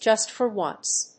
アクセント(jùst) for ónce